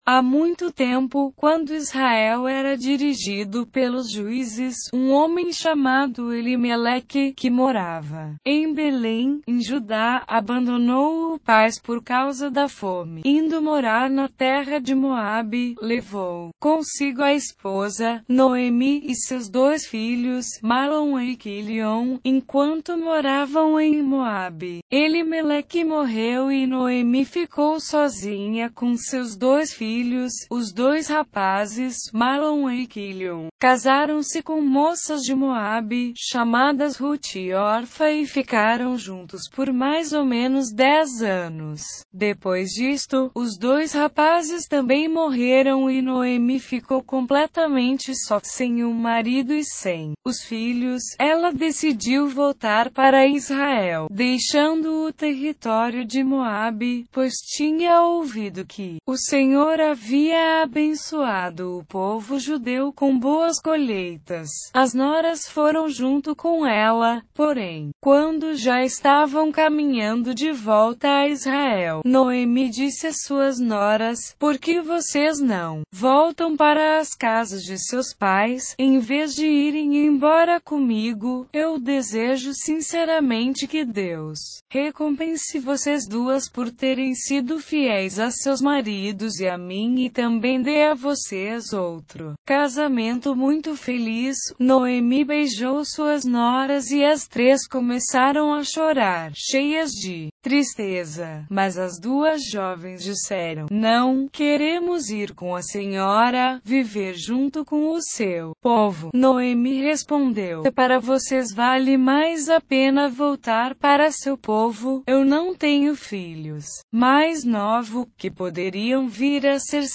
Reading in version Novo testamento Versão Palavra Viva/Velho testamento Bíblia Viva - Portuguese